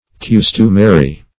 Custumary \Cus"tu*ma*ry\ (-t[-u]*m[asl]*r[y^])